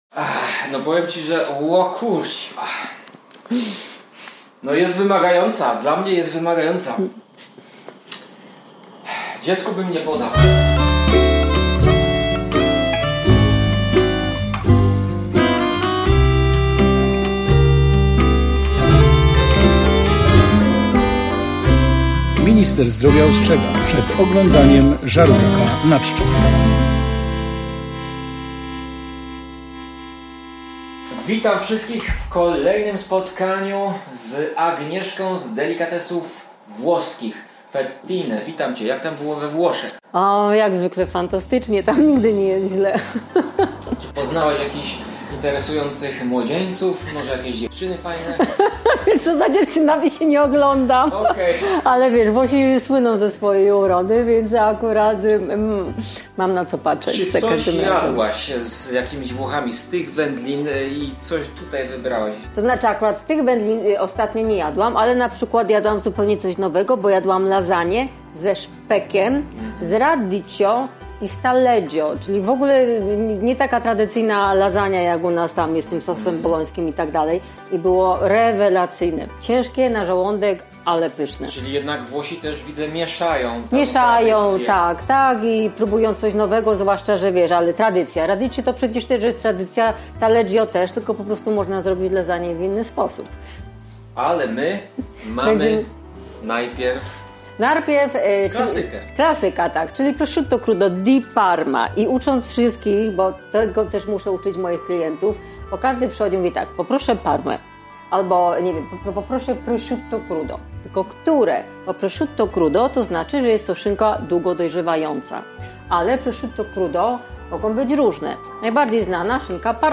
Dziś gościmy w małych włoskich delikatesach gdzie każdy produkt jest skrzętnie dobrany i przywieziony z Włoch własnym busem! Dzisiaj jemy włoskie mięso: długo dojrzewające i leżakujące szynki i wędliny. Spróbujemy prawdziwej szynki parmeńskiej z Parmy, znanej z wielu kompozycji na pizzy i nie tylko.
A wszystko to nakręcone we włoskich delikatesach w Krakowie Fettine.